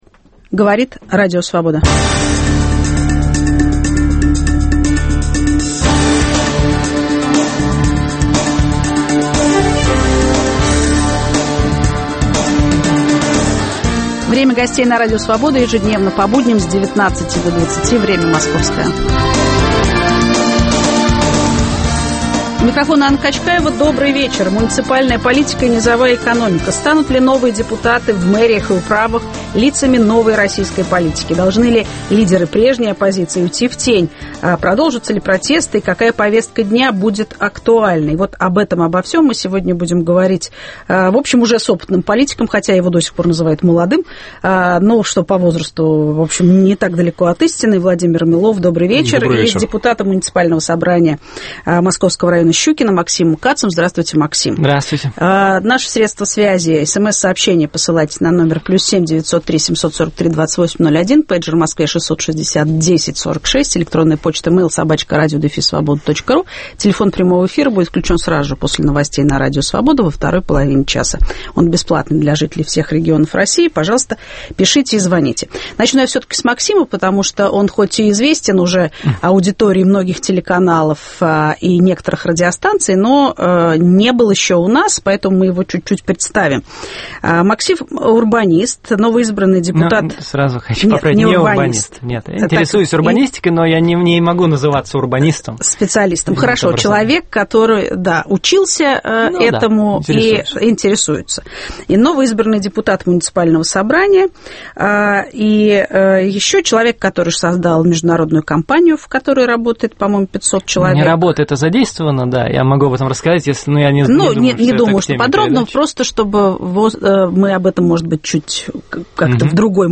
Продолжатся ли протесты и какая повестка дня будет актуальной? В студии - политик Владимир Милов и депутат муниципального собрания московского района Щукино Максим Кац.